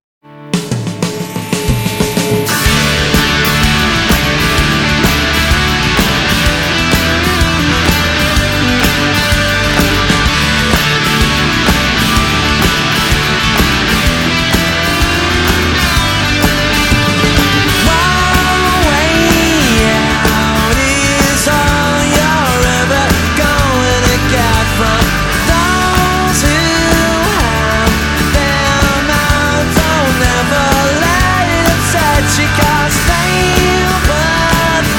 Жанр: Поп музыка / Рок / Альтернатива